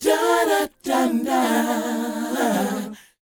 DOWOP F DD.wav